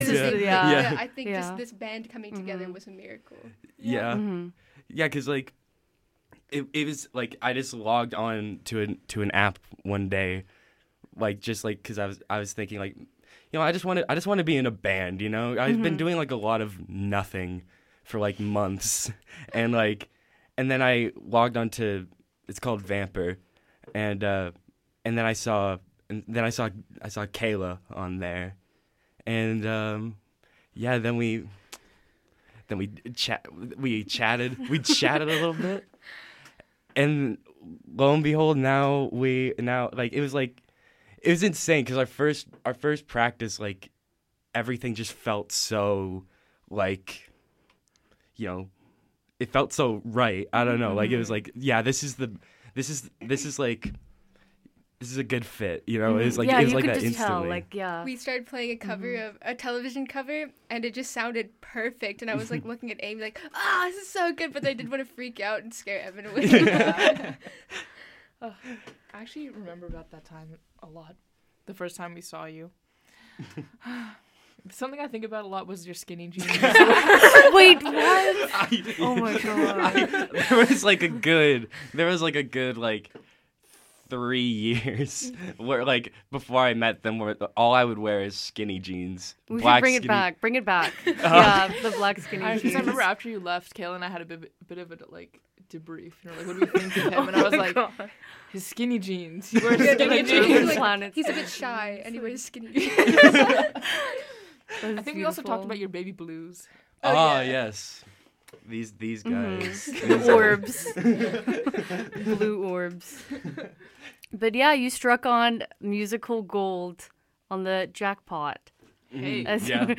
Muppet Boys mark their return to Studio A with an intriguing kiki on the origins and birth of their first single, join us live as it makes its FM radio debut...listen along and you may even find that you, dear listener, are a Miracle<3 [gob 16, 0520]